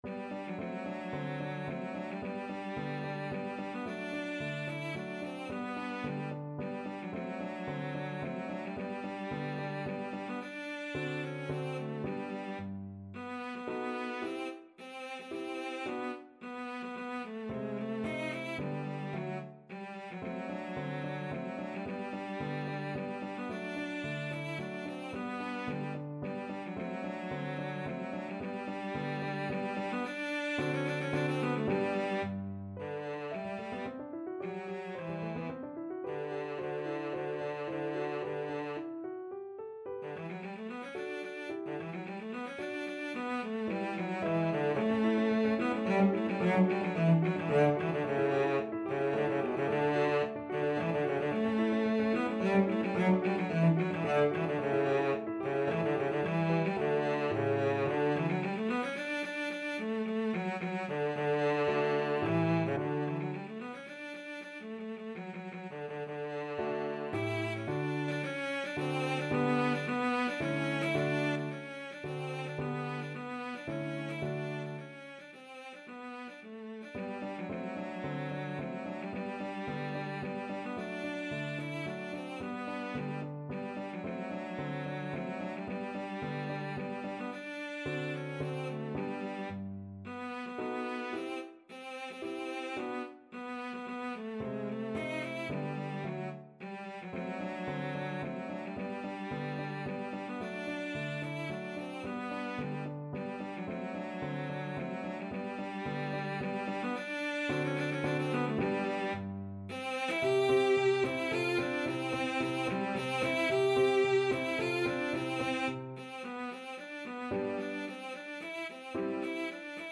Cello version
3/4 (View more 3/4 Music)
Classical (View more Classical Cello Music)